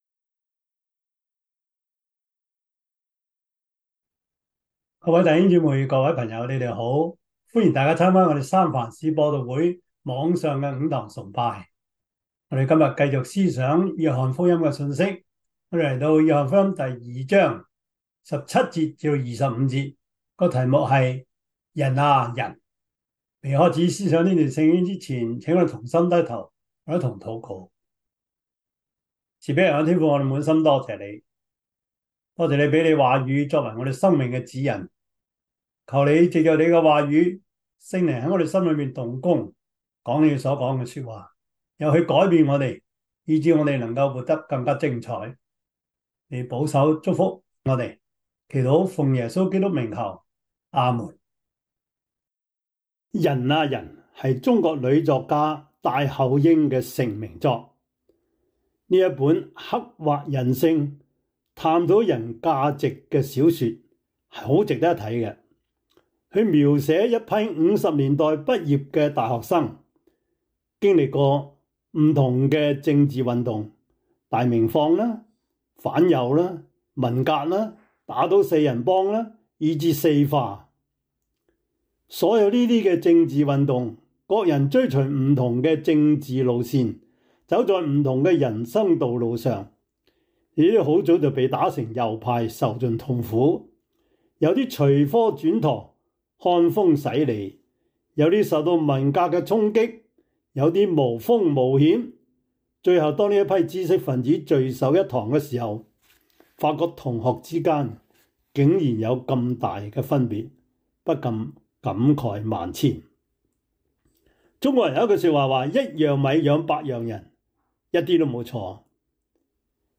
約翰福音 2:17-25 Service Type: 主日崇拜 約翰福音 2:17-25 Chinese Union Version